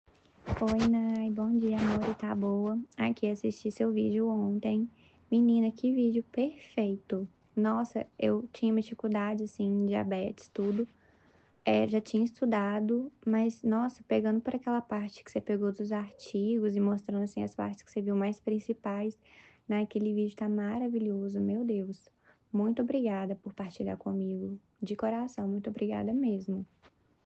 Alguns Áudios dos Alunos
AUDIO-2-DEPOIMENTO.m4a